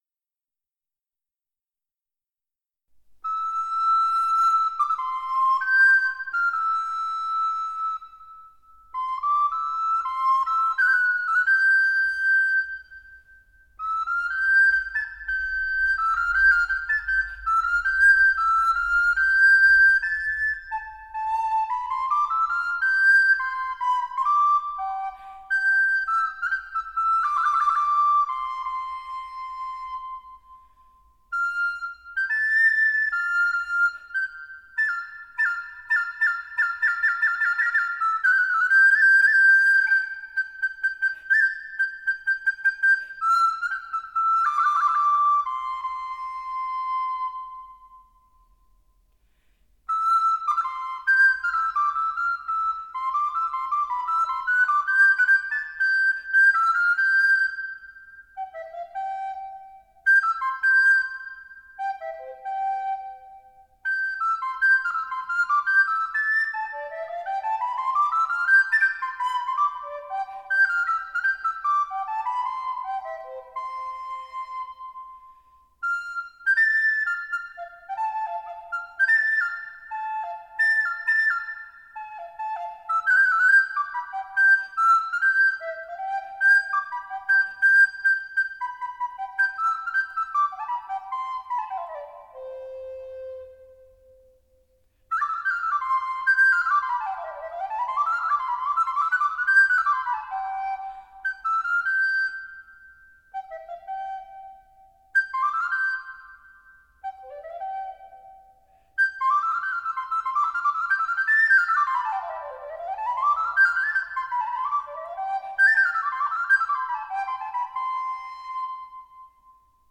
Engel Nachtigaeltje Flöte und Orgel